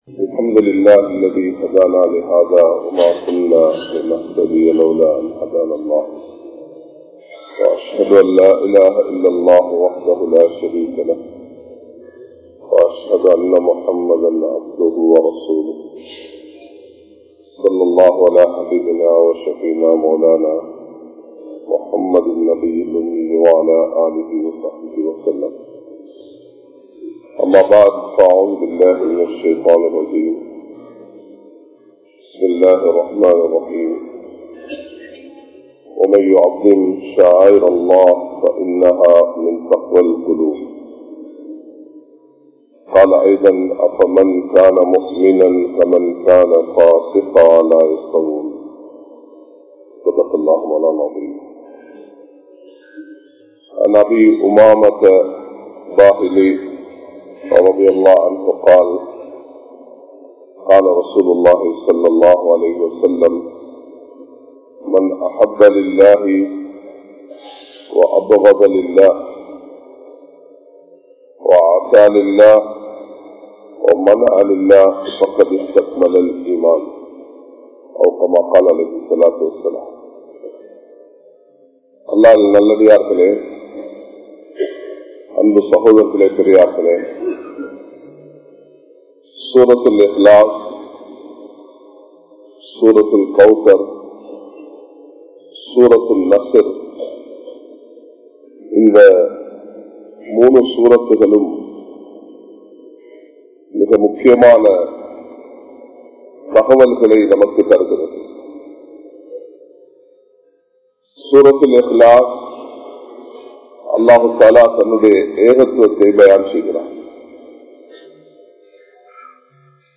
Allahvukkaaha Vaalvoam (அல்லாஹ்வுக்காக வாழ்வோம்) | Audio Bayans | All Ceylon Muslim Youth Community | Addalaichenai
Colombo, GrandPass Markaz